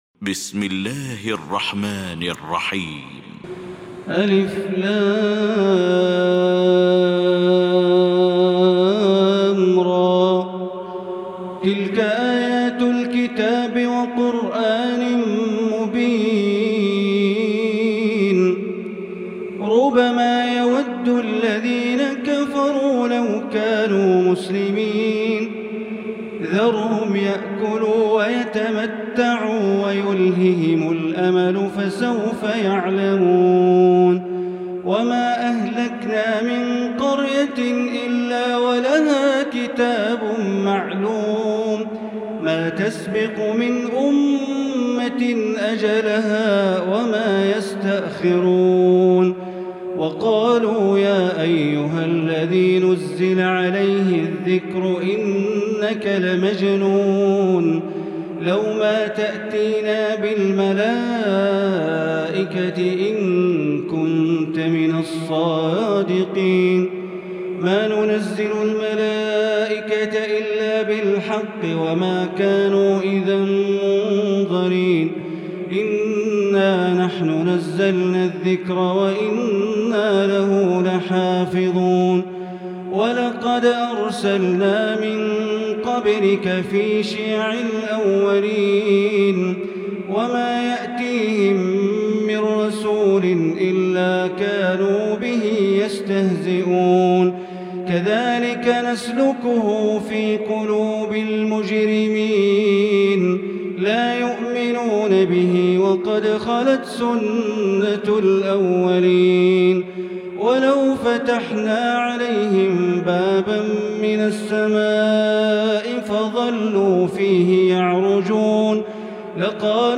المكان: المسجد الحرام الشيخ: سعود الشريم سعود الشريم فضيلة الشيخ ياسر الدوسري الحجر